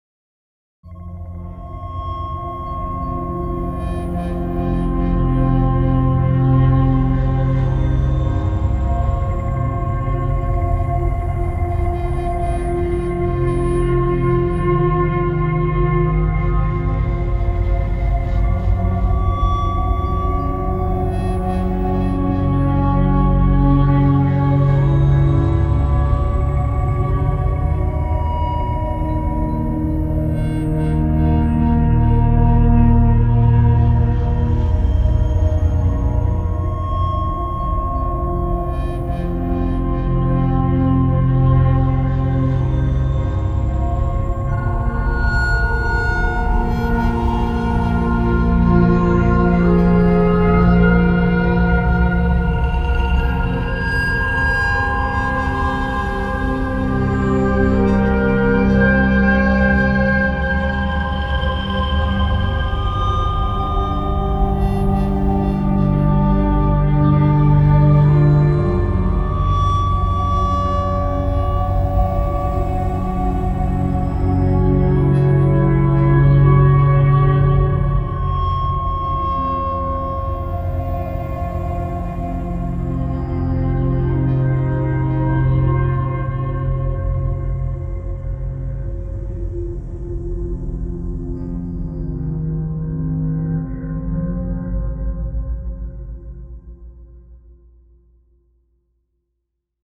Horror Atmosphere Sound Effect Free Download
Horror Atmosphere